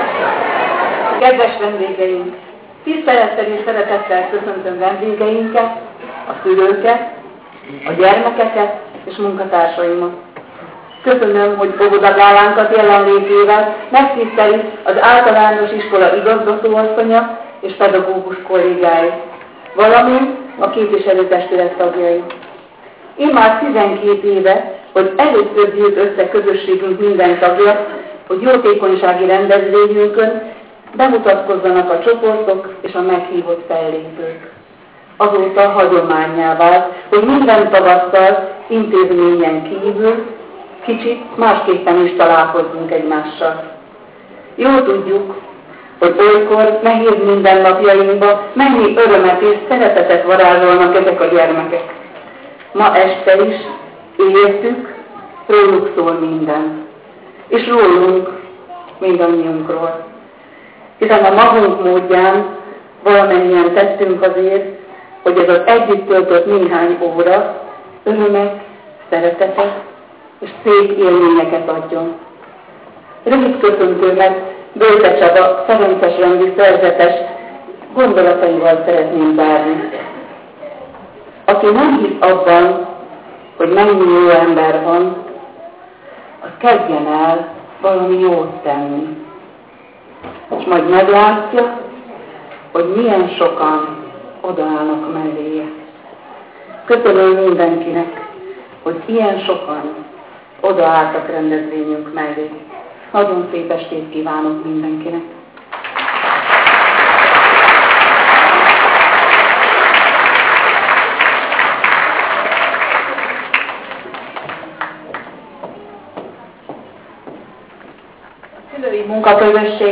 Majd rövid köszöntők következtek.